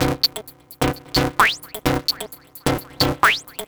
Flyspit B 130.wav